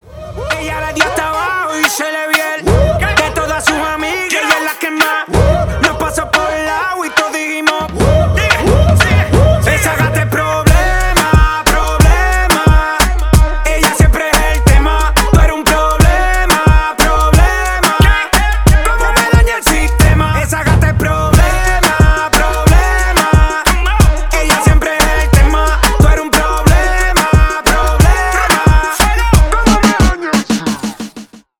Поп Музыка # Танцевальные